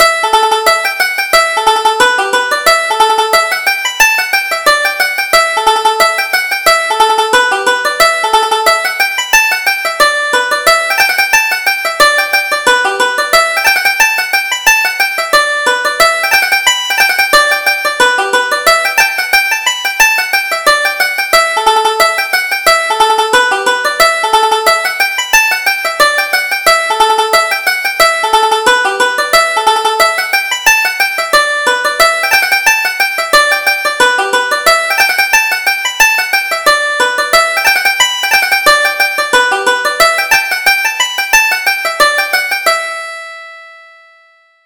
Reel: The Merry Harriers - 2nd Setting